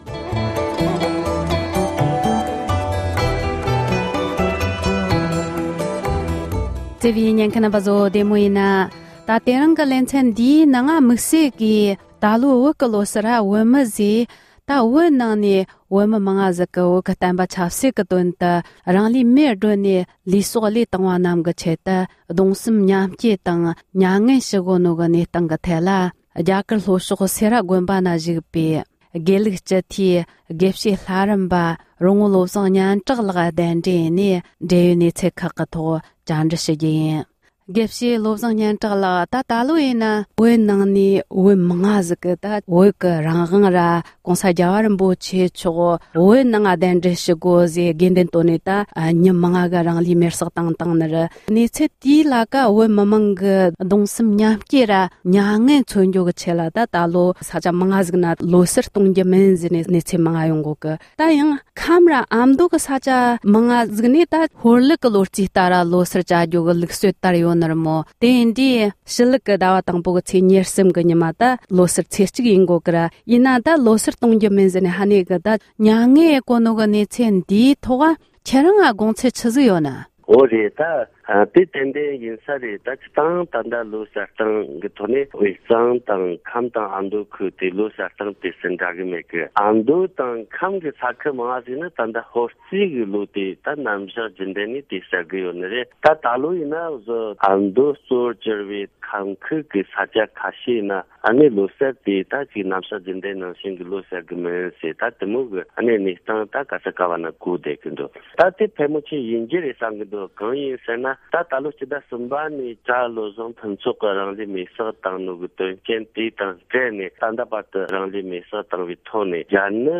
བཅར་འདྲི